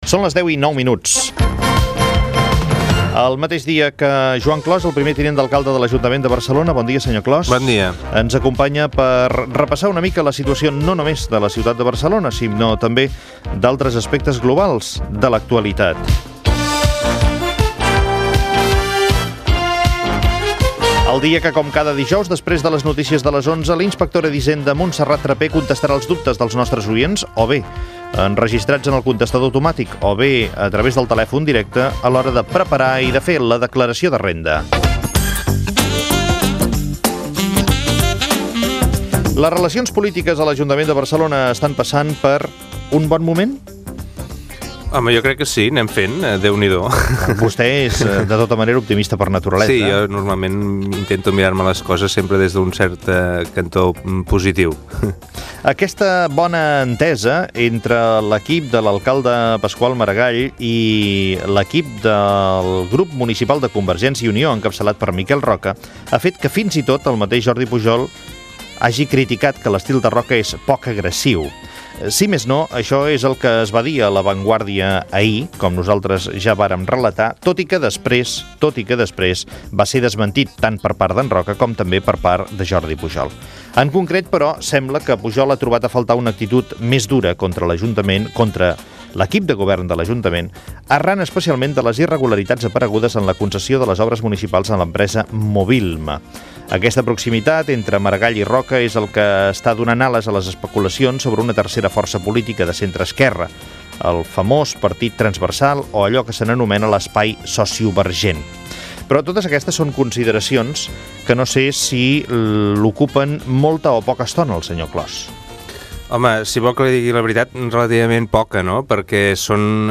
Hora i fragment d'una entrevista al tinent d'alcalde de l'Ajuntament de Barcelona, Joan Clos.
Info-entreteniment